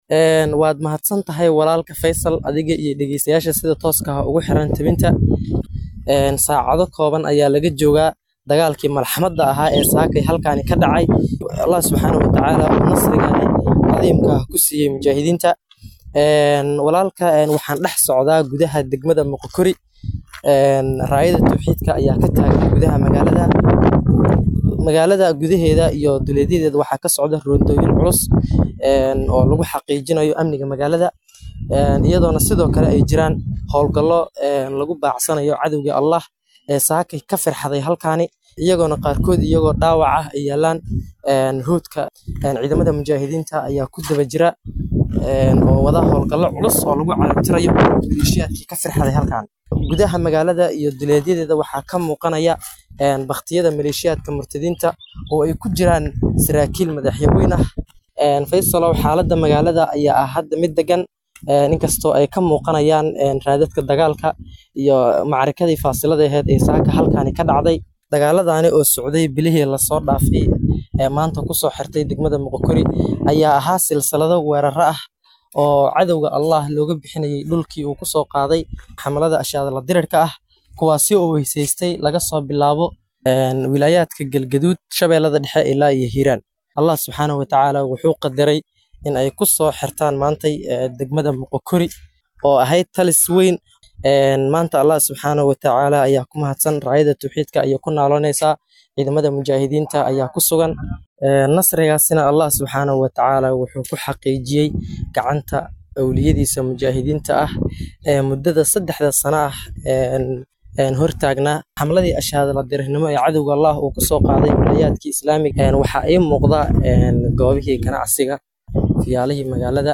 Waraysiga